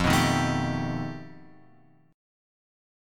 F 9th Flat 5th